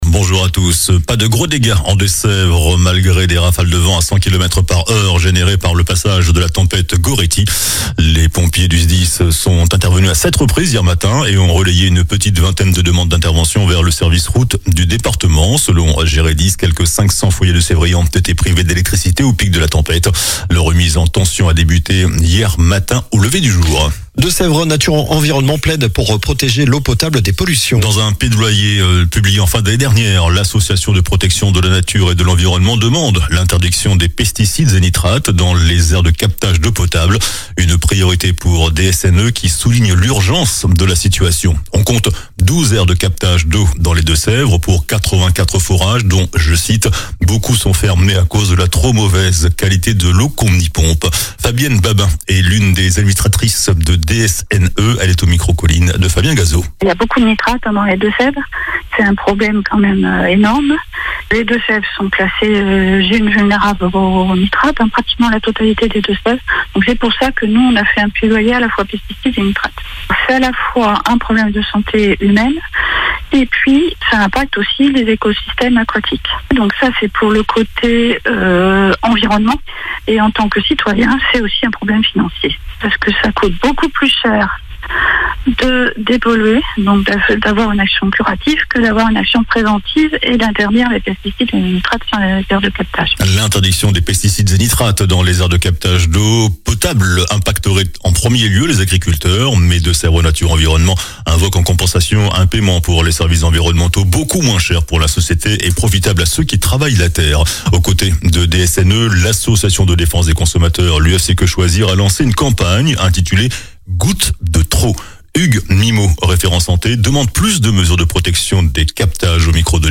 JOURNAL DU SAMEDI 10 JANVIER